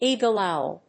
/ˈiːɡəlˈɑʊl(米国英語)/